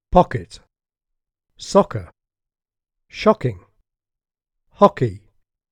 The problematic non-English pronunciation is one which makes the stressed vowel too short and/or too open in quality: too much like the LOT vowel, as in pocket, soccer, shocking and hockey:
pocket_etc.mp3